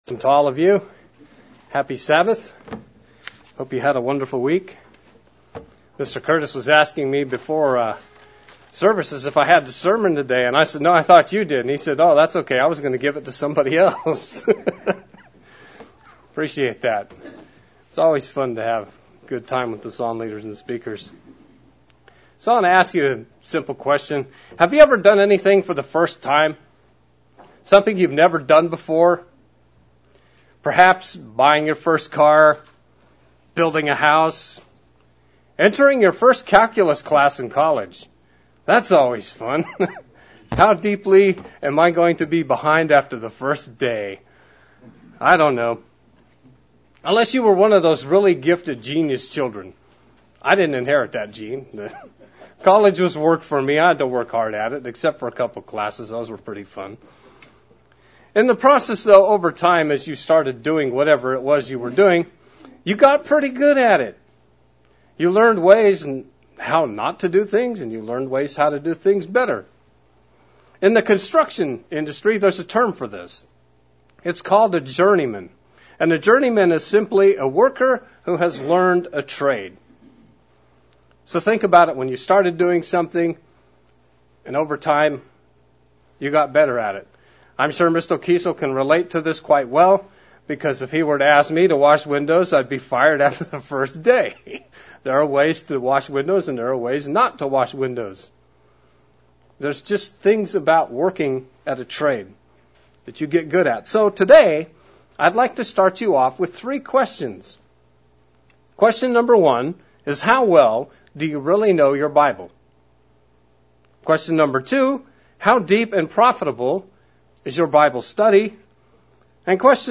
Given in Central Illinois